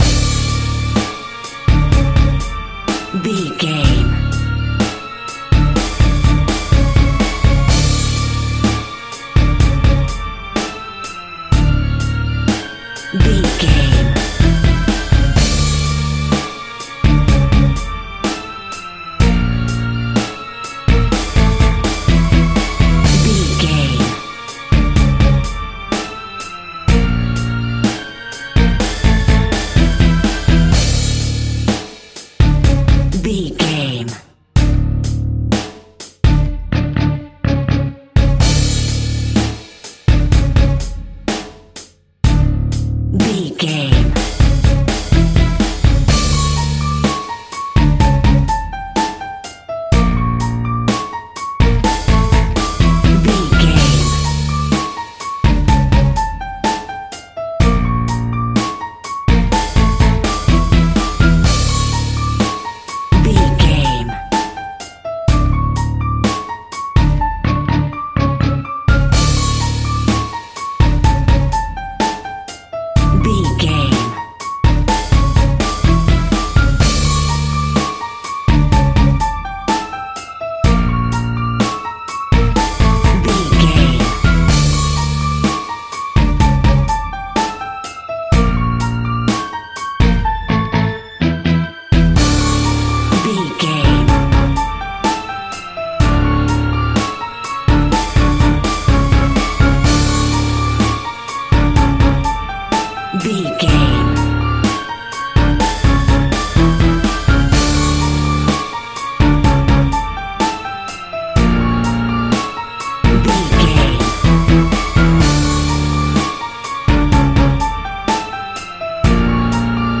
Heavy Scary Rock.
Aeolian/Minor
D
scary
ominous
dark
haunting
eerie
strings
bass guitar
drums
piano
synth
pads